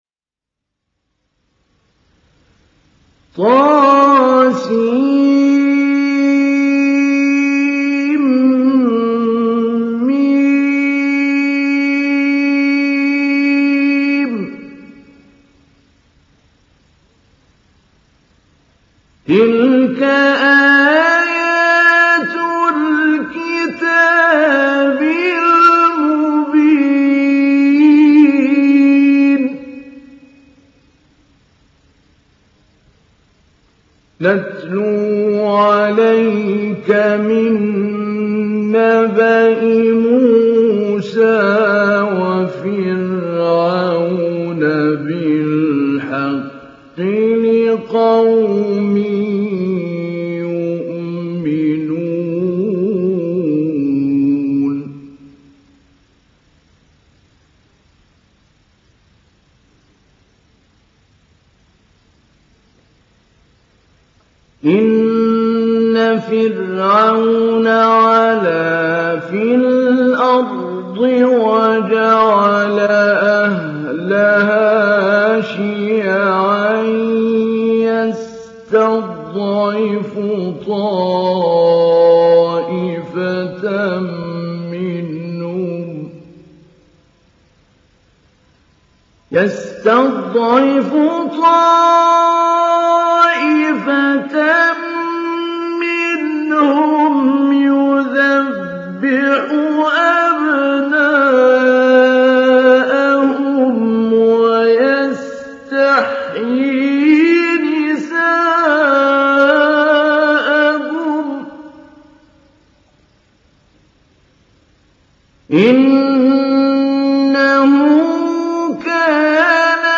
تحميل سورة القصص محمود علي البنا مجود